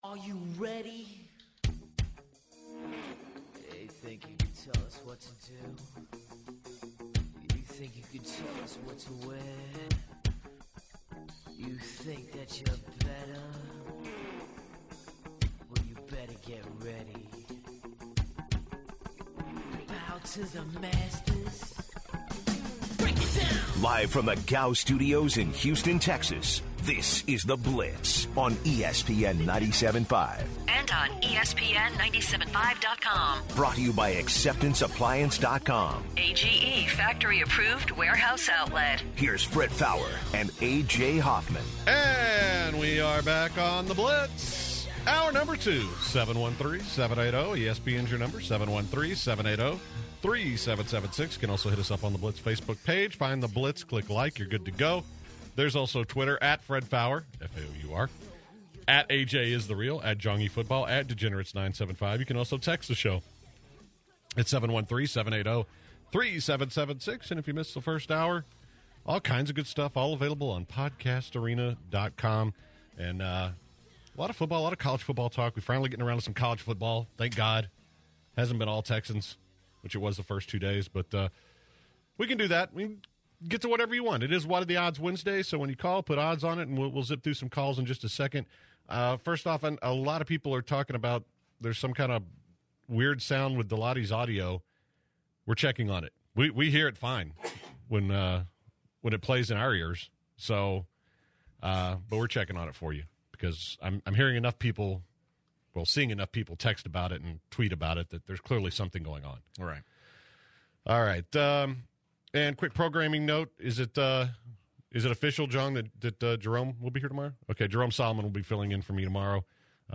This hour the guys go over what is reasonable journalism and take caller questions. Who will stay on the Texans coaching staff after the season?